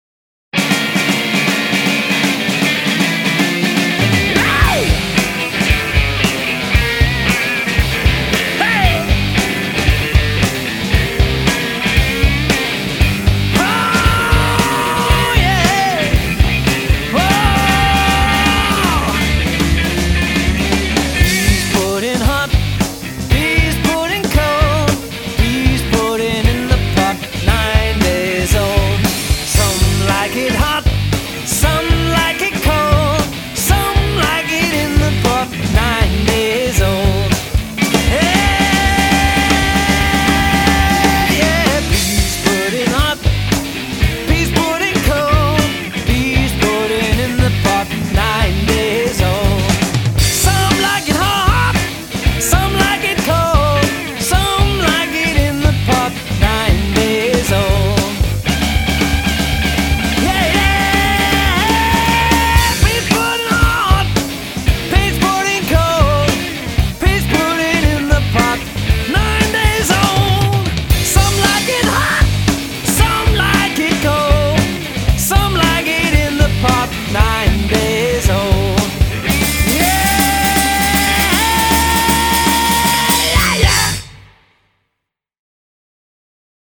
Fun & PunkRock